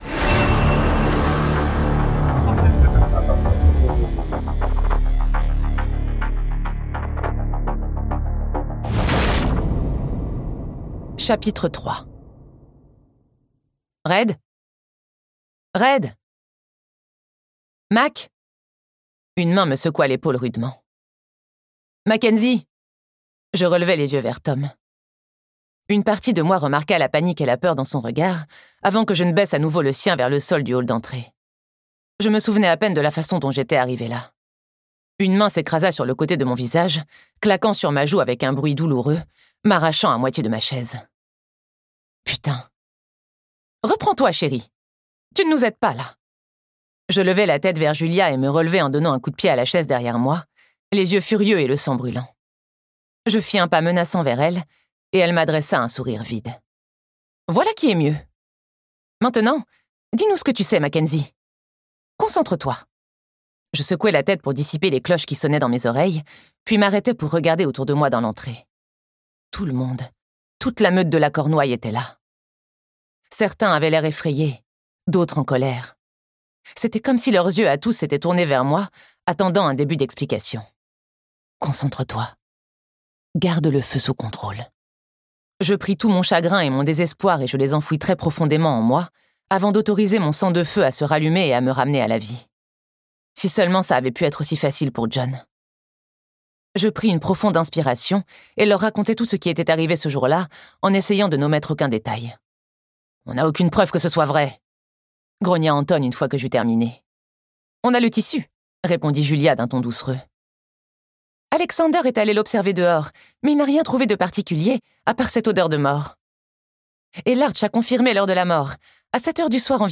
Et malheureusement, Corrigan, Seigneur Alpha de la Fraternité, montagne de muscles aux yeux verts, n'est pas du genre à se laisser facilement duper...Ce livre audio est interprété par une voix humaine, dans le respect des engagements d'Hardigan.